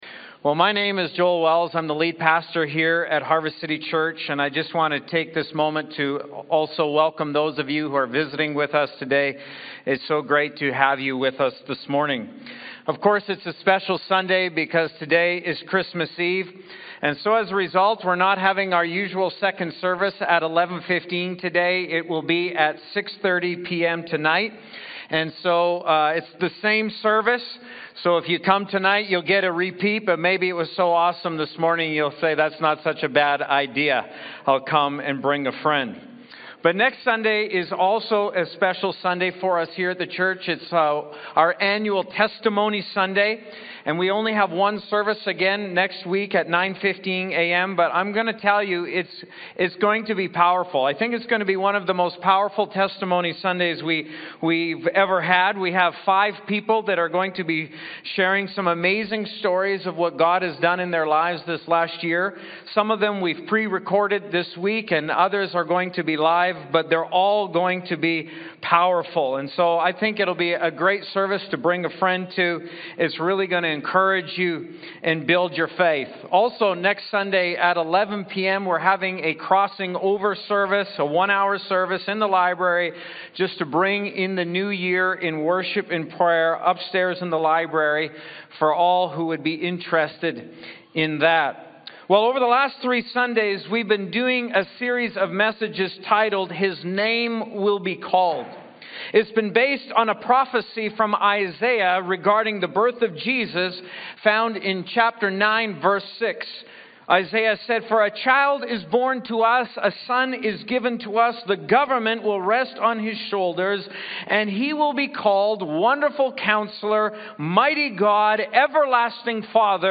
Christmas sermon series